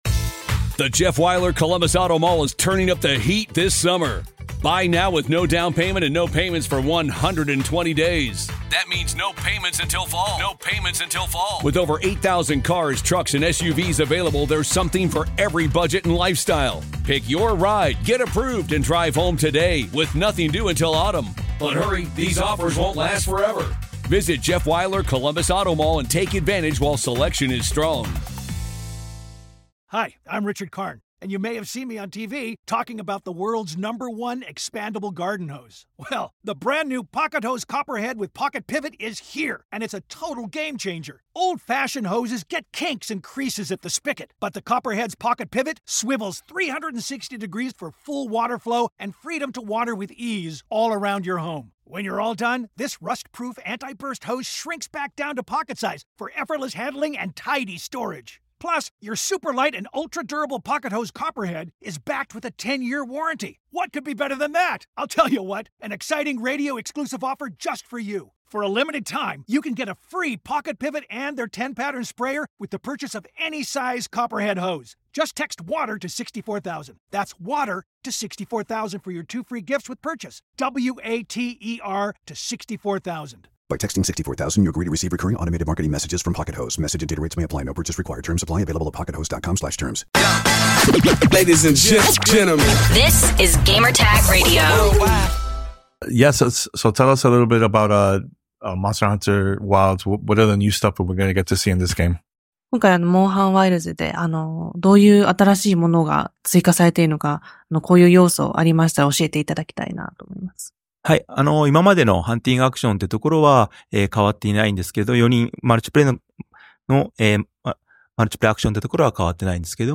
Monster Hunter Wilds Interview